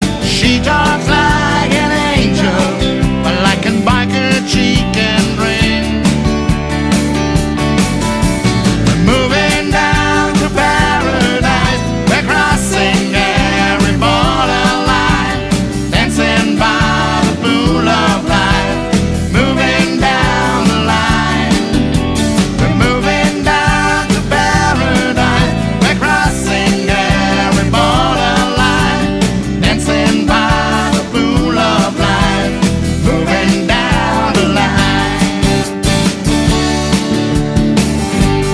Singer/Songwriter
Live, ohne Netz und doppelten Boden.
CD-TRACKS (ANSPIELPROBE)